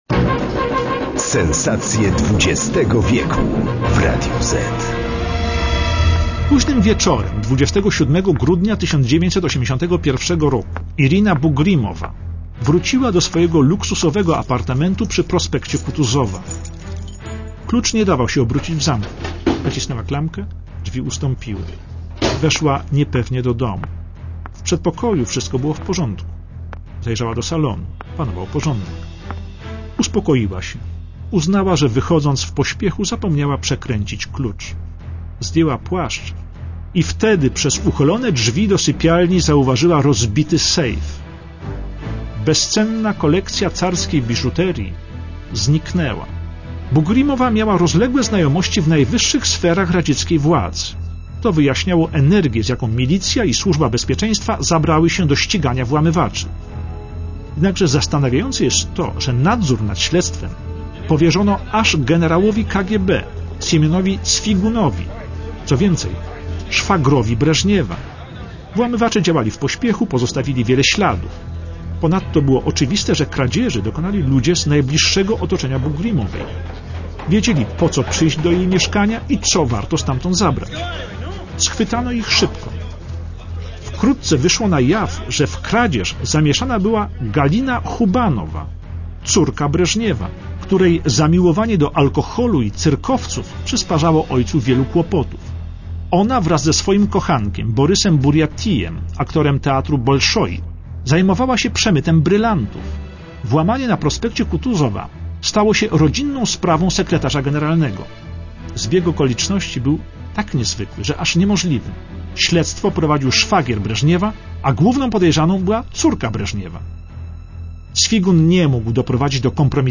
Aktorzy:
Jurij Andropow - Krzysztof Kowalewski